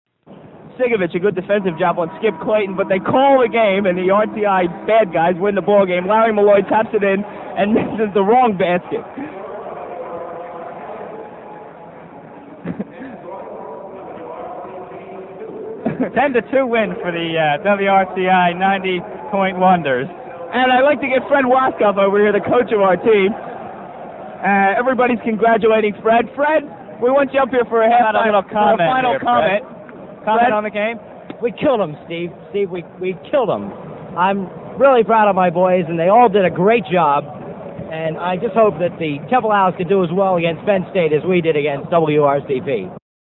The game took place in Philadelphia's famous Palestra, at half-time of a real game between Temple and Penn State.
For that, we have the actual play-by-play audio.